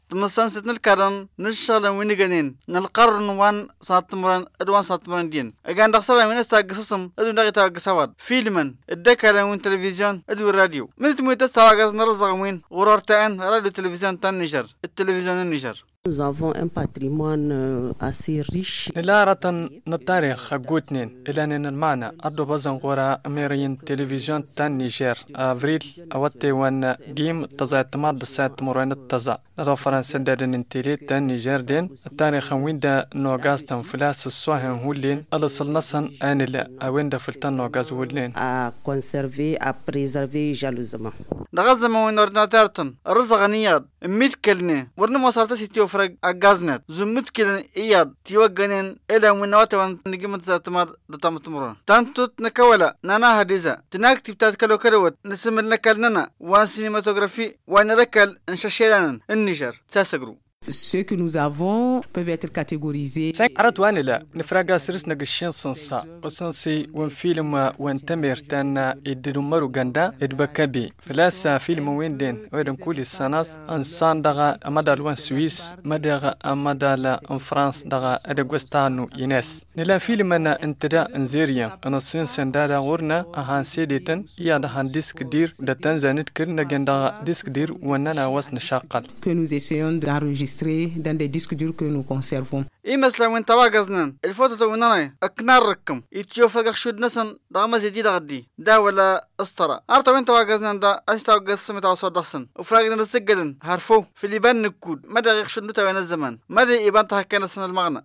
Hier 27 octobre 2016, c’était la Journée Internationale du patrimoine audiovisuel. La sauvegarde et la conservation des images en mouvement, marque l’importance culturelle et historique des archives cinématographiques et télévisuelles. Reportage